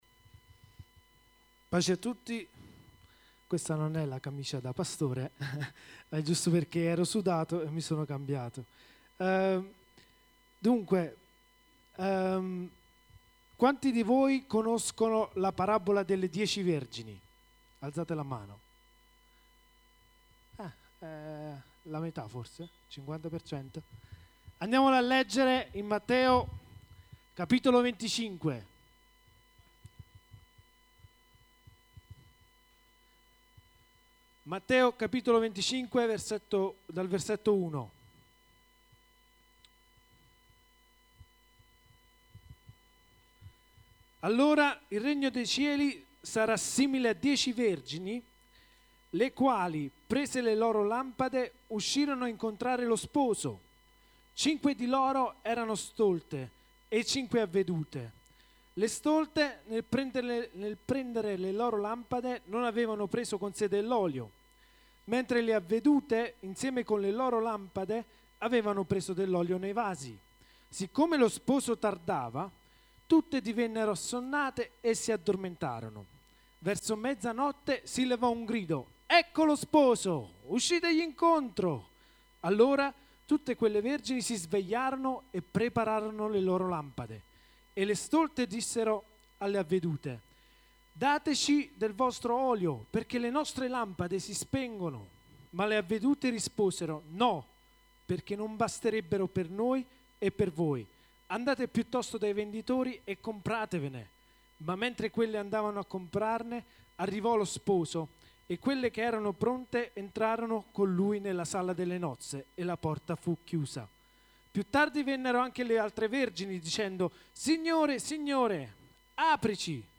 Preparati per le nozze dell'Agnello - Comunità Cristiana Evangelica Montesarchio